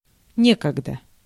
Ääntäminen
Synonyymit раз однажды когда-то Ääntäminen Haettu sana löytyi näillä lähdekielillä: venäjä Käännös Ääninäyte Adverbit 1. in the old days 2. in former times 3. formerly US 4. once US Translitterointi: nekogda.